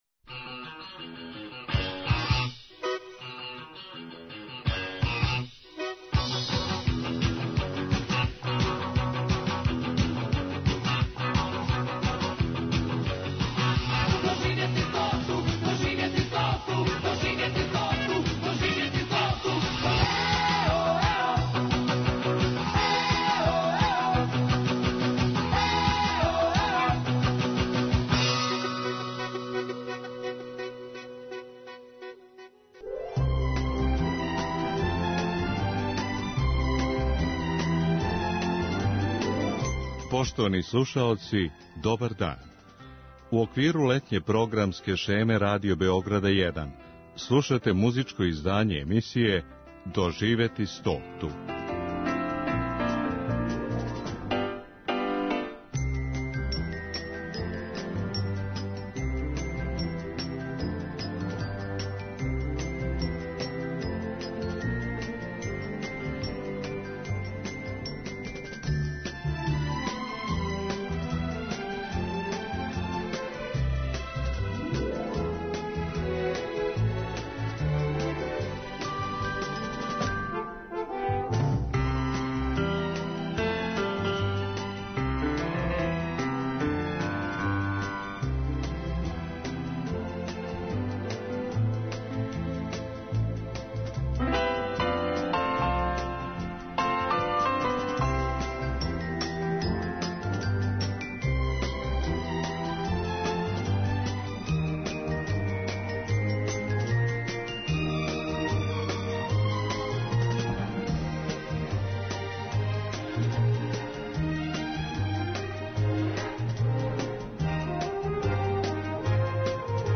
са одабраним евергрин песмама